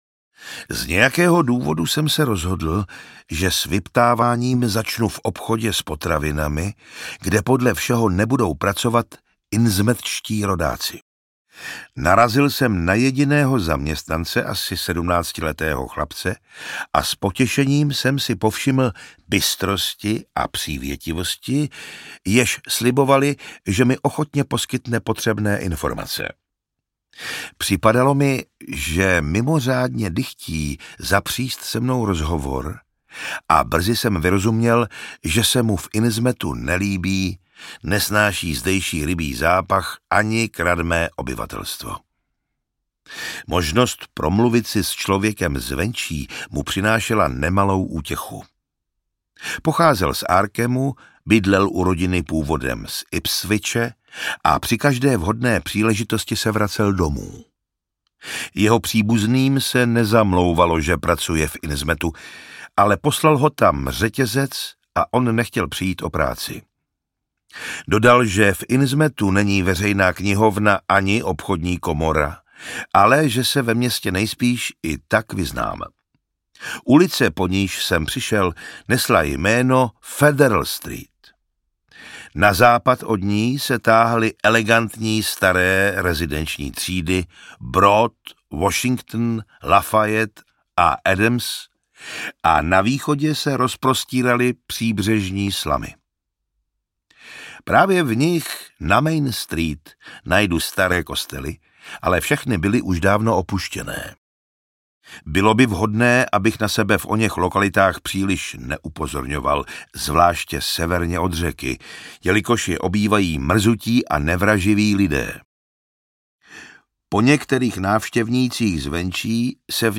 Stín nad Innsmouthem audiokniha
Ukázka z knihy
| Hudba Soundguru.
| Vyrobilo studio Soundguru.